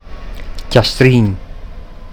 Italienisch-mundartliche Form
[casˈtrin]
Castrin_Mundart.mp3